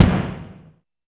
84 KICK   -L.wav